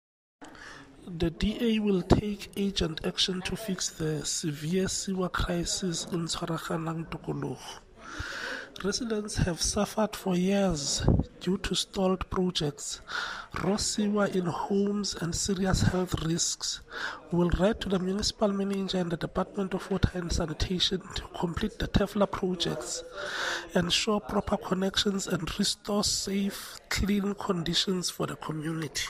Sesotho soundbites by Cllr Hismajesty Maqhubu.